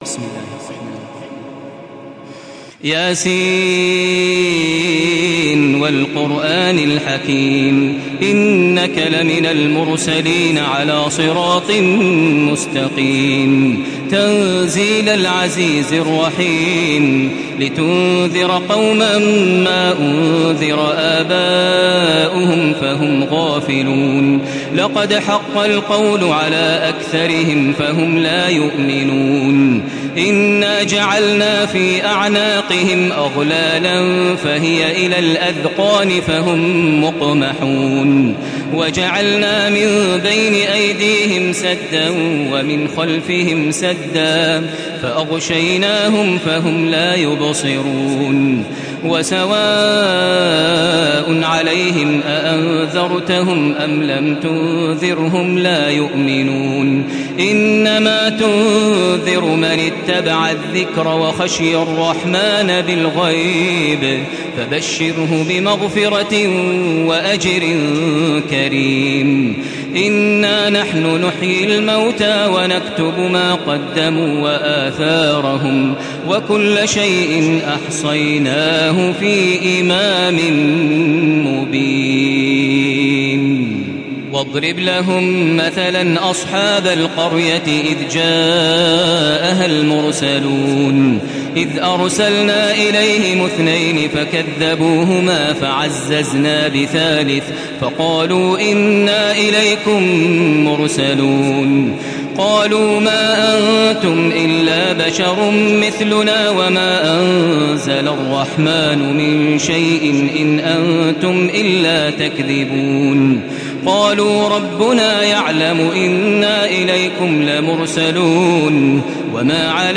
تراويح الحرم المكي 1435
مرتل